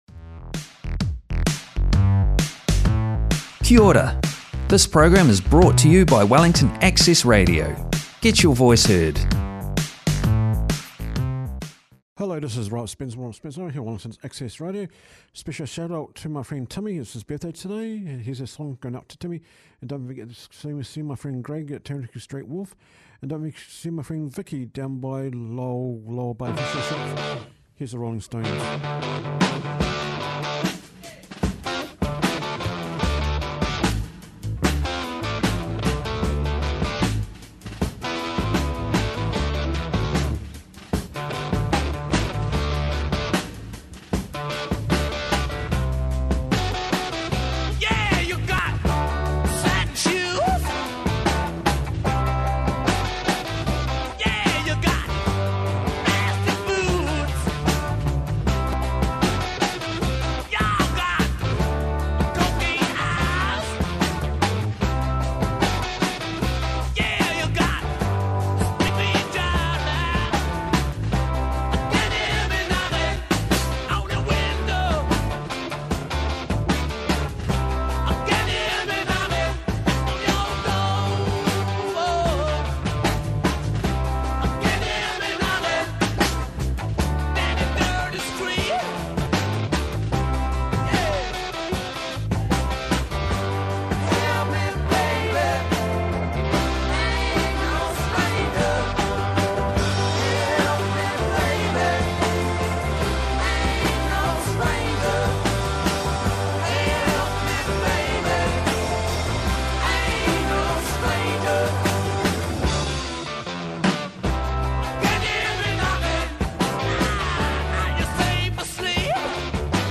Tune in for a great mix of music and interviews with special guests.